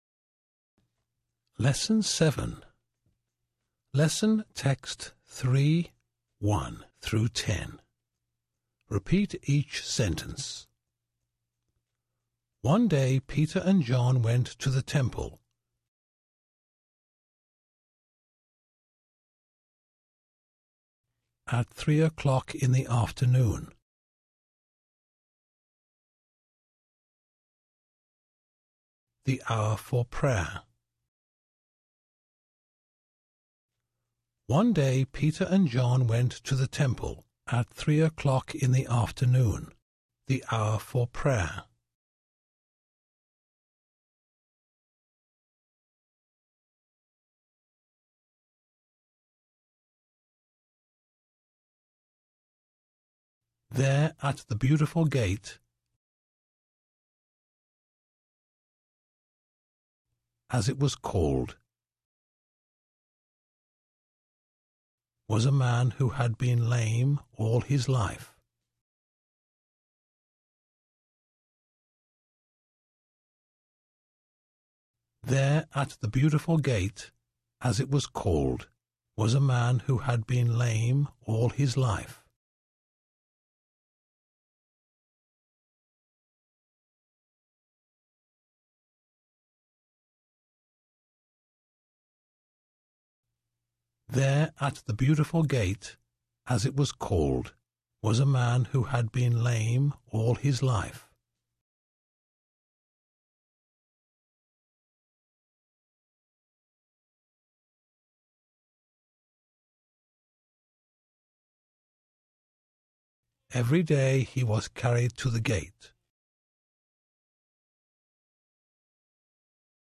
在线英语听力室免费英语课程培训-British Lesson 7ab的听力文件下载,免费英语课程培训,纯外教口语,初级学习-在线英语听力室